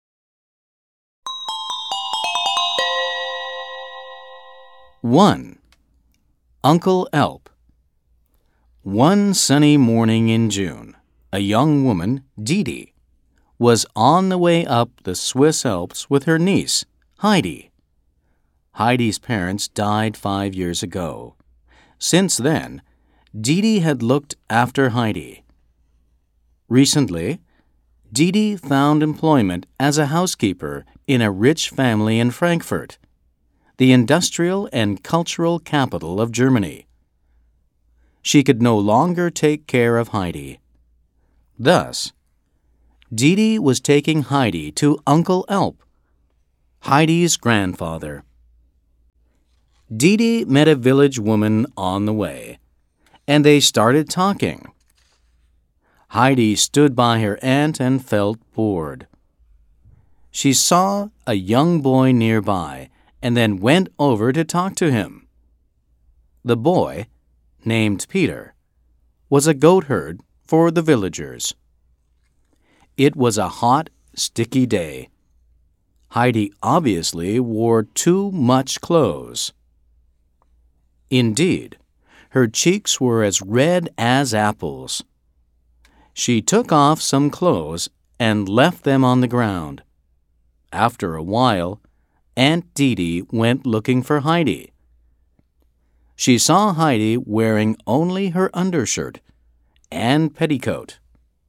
本書生動活潑的朗讀音檔，是由專業的美國播音員所錄製；故事是由以英文為母語的專業編輯，參照教育部公布的英文字彙改寫而成，對於所有學生將大有助益。
In the audio recording of the book, texts are vividly read by professional American actors.